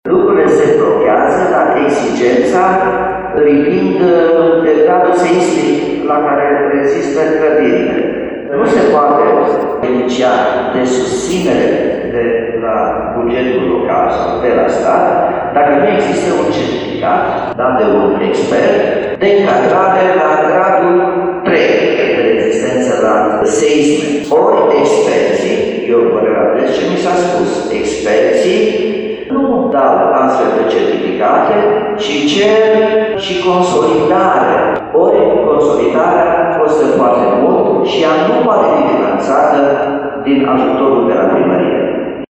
Asta pentru că proprietarii clădirilor din zona Cetate au nevoie de un aviz pentru clasa de risc seismic al clădirilor pentru a beneficia de avantajele împrumutului oferit de primărie, spune primarul Nicolae Robu.